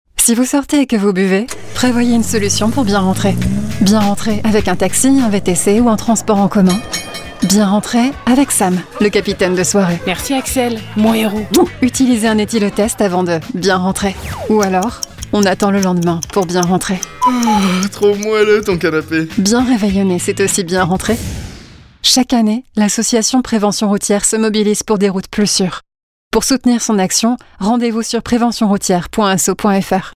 Le spot radio Bien Rentrer :